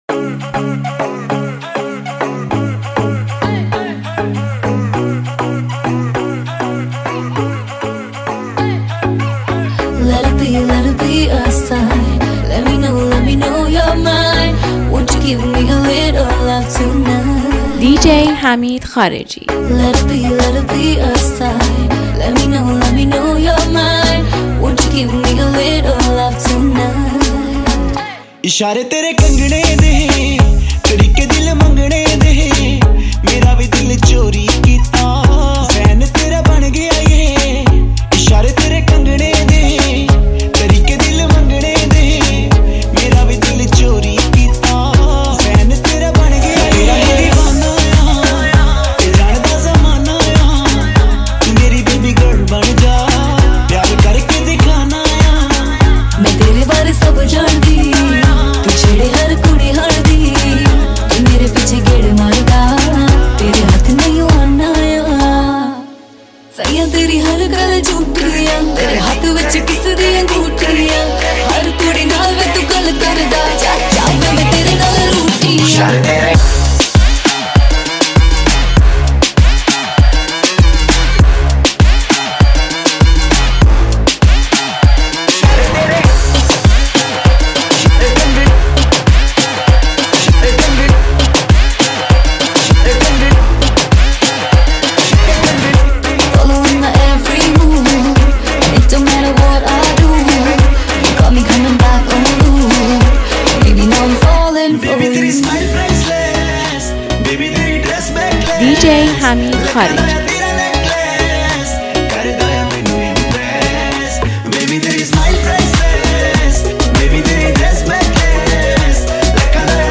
ریتم‌های جذاب